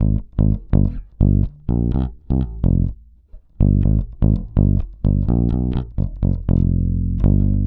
Index of /90_sSampleCDs/Best Service ProSamples vol.48 - Disco Fever [AKAI] 1CD/Partition D/BASS-FINGER